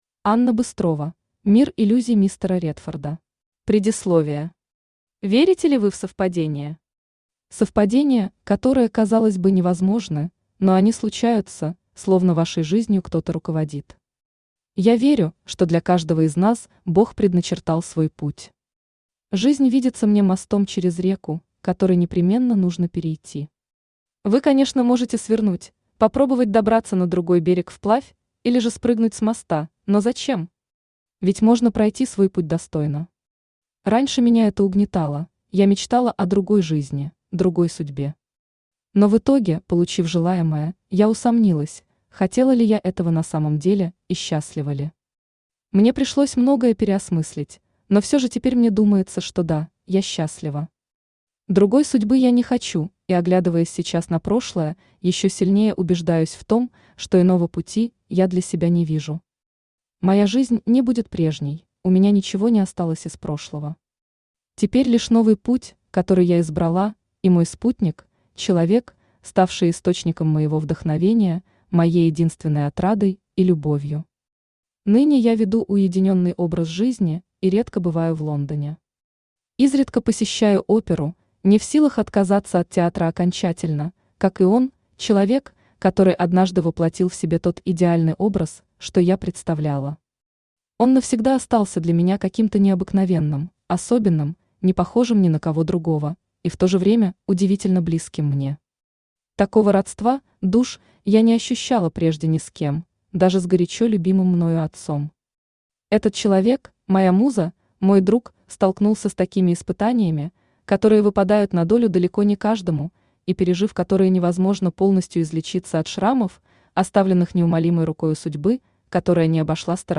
Аудиокнига Мир иллюзий мистера Редфорда | Библиотека аудиокниг
Aудиокнига Мир иллюзий мистера Редфорда Автор Анна Быстрова Читает аудиокнигу Авточтец ЛитРес.